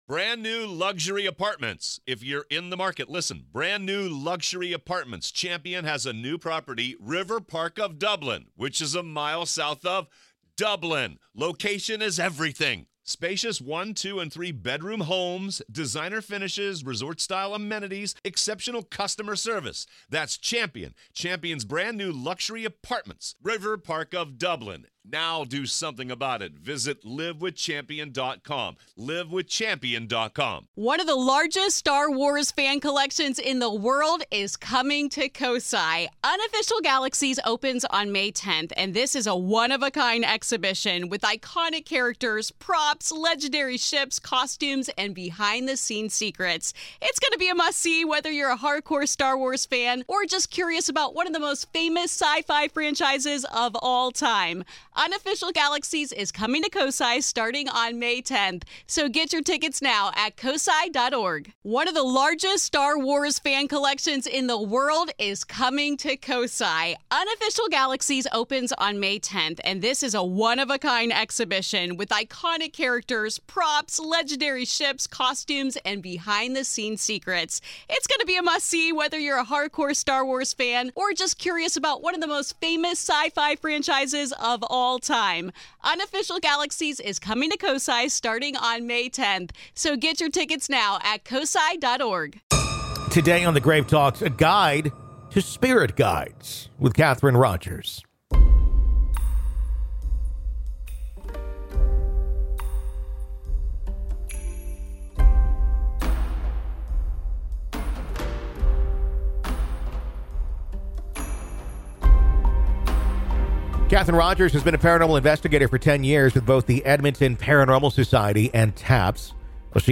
Science, Religion & Spirituality, Society & Culture, Personal Journals, Spirituality, Natural Sciences